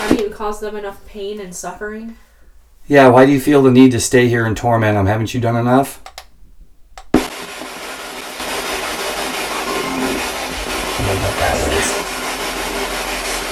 Spirit Box Clip 3 Villisca Axe Murder House Spirit Box Clip 3 Another amazing SB11 spirit box response that needs liitle intro. The SB11 has a "mute" button, allowing us to silence the noise.
I was standing IN the attic closet upstairs, in the exact spot where the killer (if there was only one) may have hidden until the family fell asleep before he came out and killed them all.
Very dark voice makes disturbing comment A very EVIL voice says, "Lena passes." show/hide spoiler Back to Villisca Axe Murder House Evidence Page